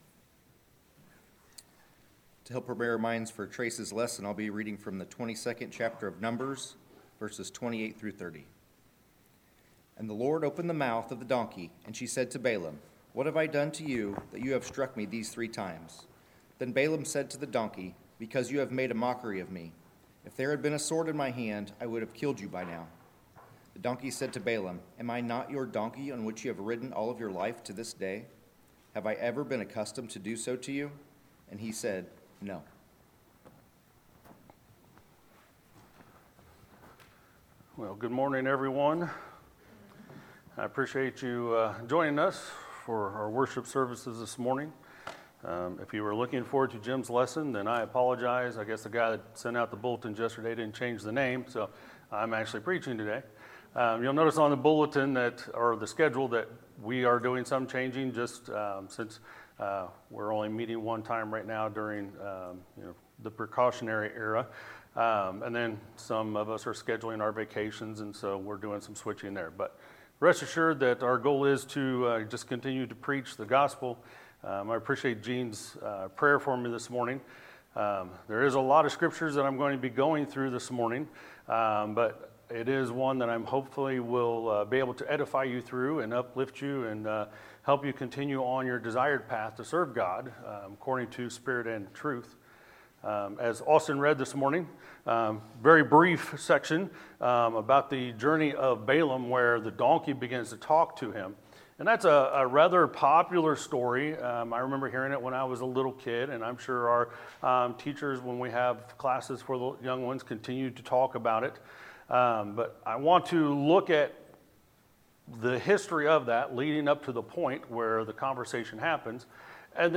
Sermons, May 31, 2020